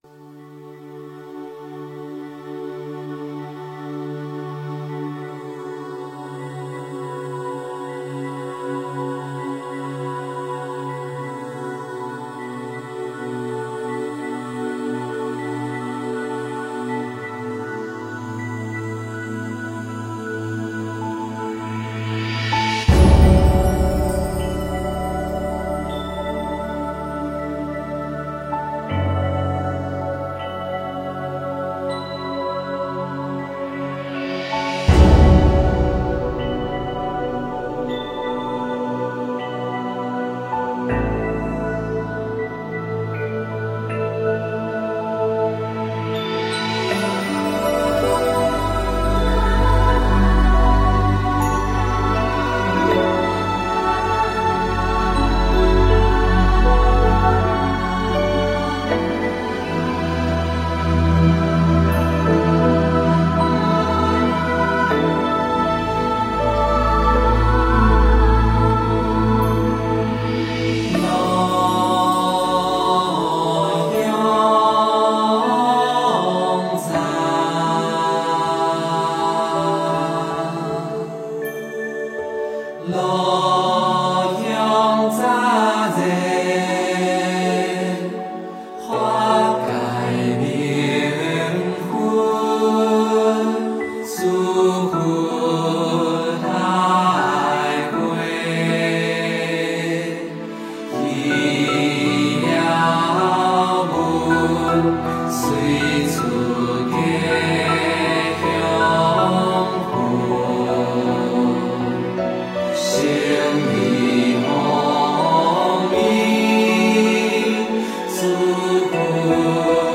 炉香赞.净口业真言.安土地真言.般若波罗蜜多心经.回向--新韵传音 经忏 炉香赞.净口业真言.安土地真言.般若波罗蜜多心经.回向--新韵传音 点我： 标签: 佛音 经忏 佛教音乐 返回列表 上一篇： 道德经--新韵传音 下一篇： 弥勒救苦真经--新韵传音 相关文章 六字大明咒(唱诵版)--宗南嘉楚仁波切 六字大明咒(唱诵版)--宗南嘉楚仁波切...